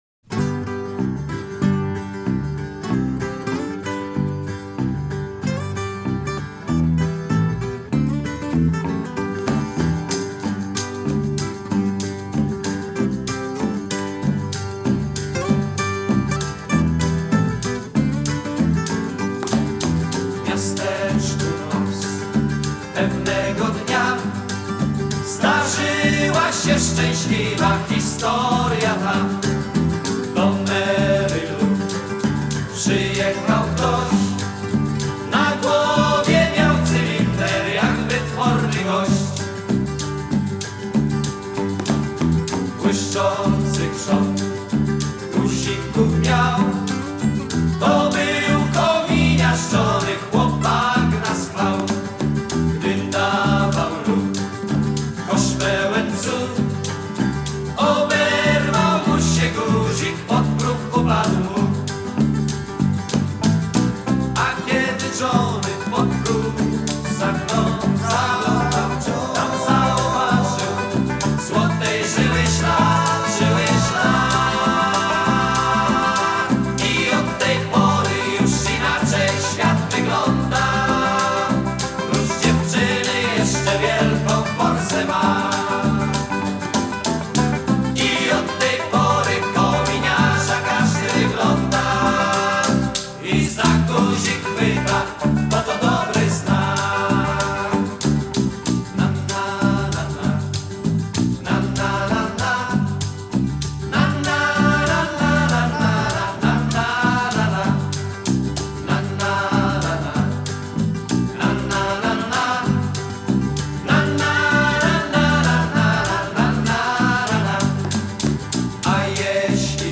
Только такое качество
Качество отличное, видимо с CD